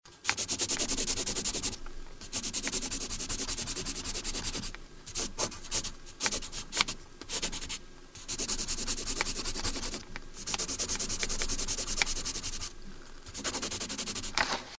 Шуршание раскраски карандашом